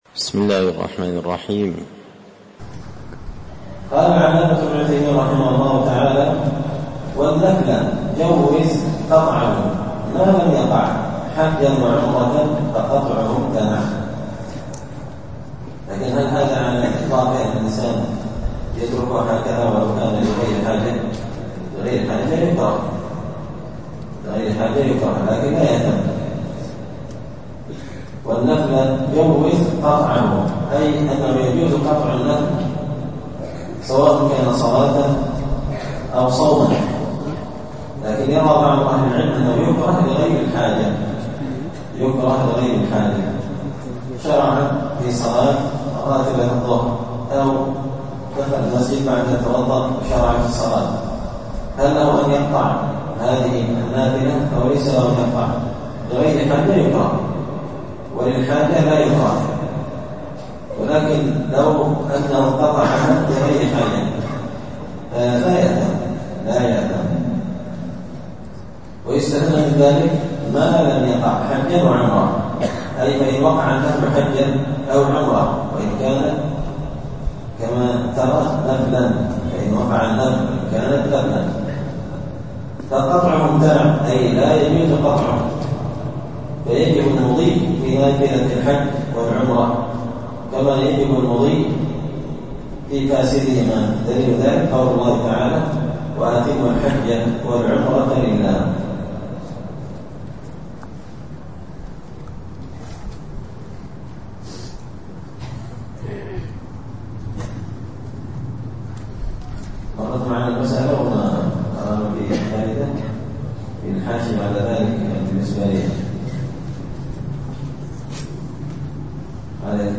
تسهيل الوصول إلى فهم منظومة القواعد والأصول ـ الدرس 31
مسجد الفرقان